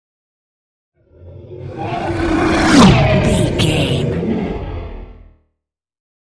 Horror creature vehicle pass by
Sound Effects
Atonal
No
scary
ominous
eerie
pass by